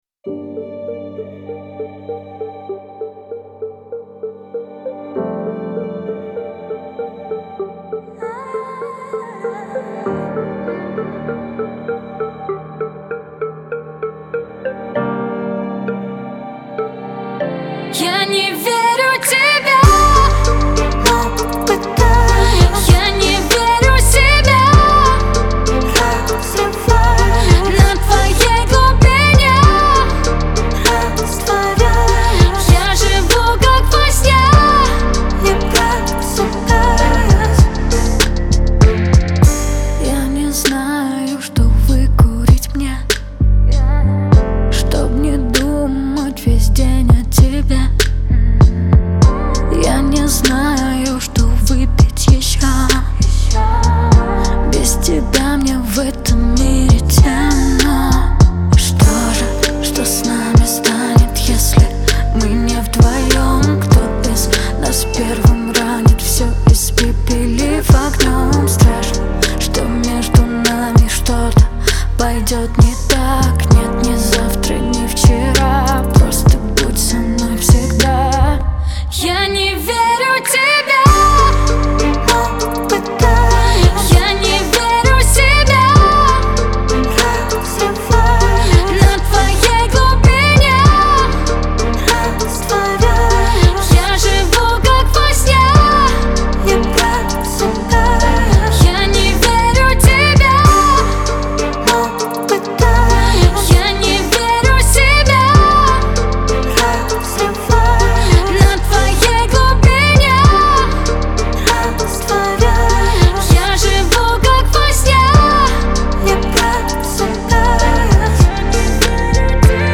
это яркая композиция в жанре поп